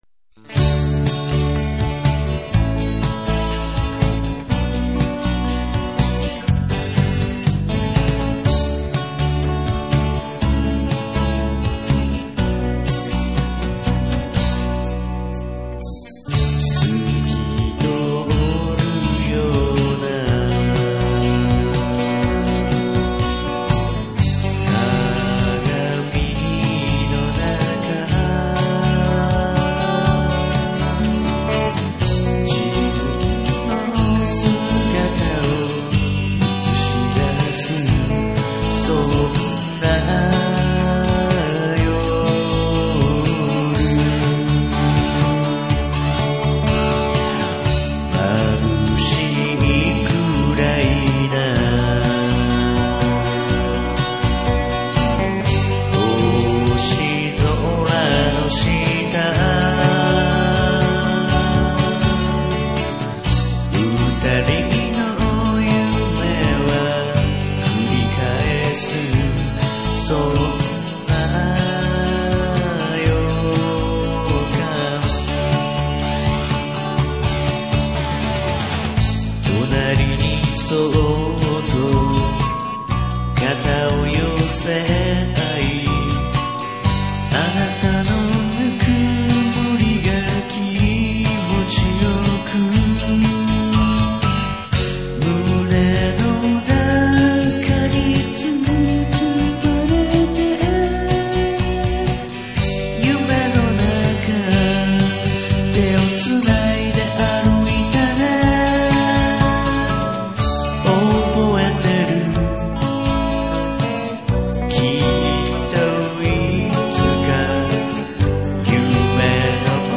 作曲、編曲には、明るく、希望、夢、現実を併せて表現してみました。
録音は、ふんわりとした感じのヴォーカル、楽器のバランスに特に注意して、 一つひとつが優しく感じられるよう意識しました。
歌は下手なので、どうしようもありません。